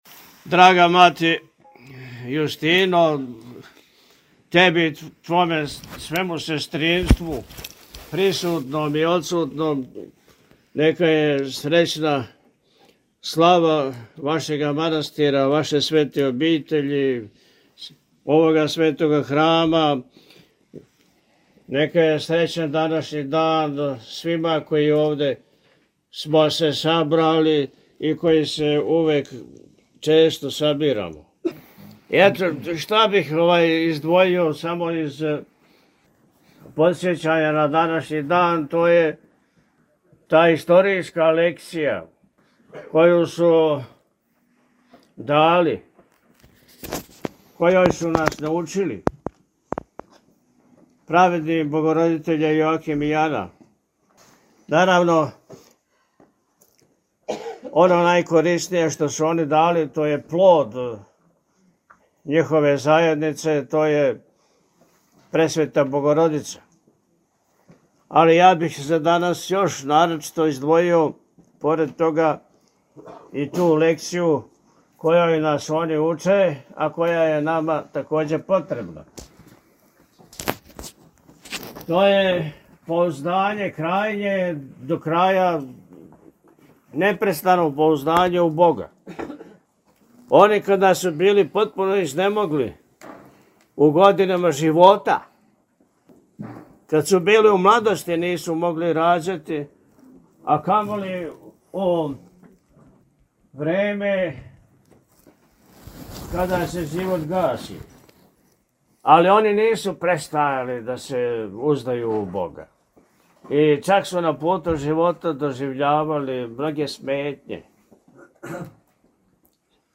Слава манастира Светих Јоакима и Ане у Рутошима - Eпархија Милешевска